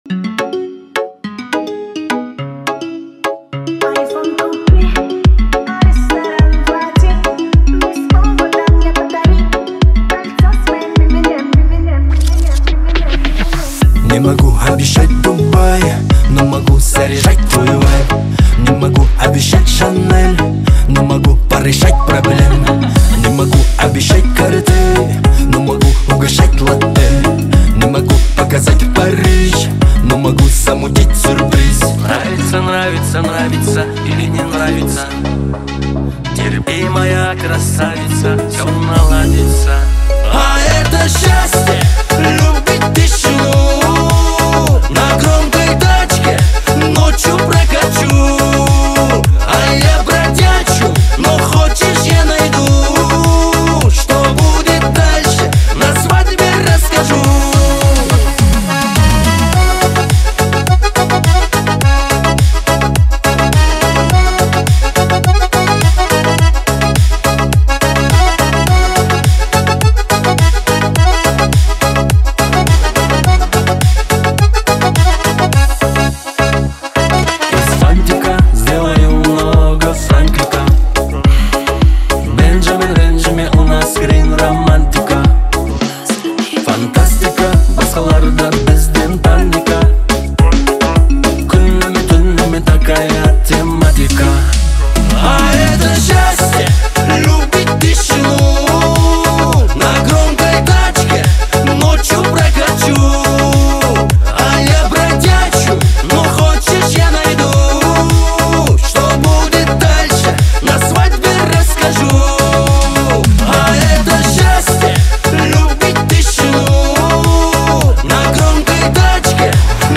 Качество: 320 kbps, stereo
Казахская музыка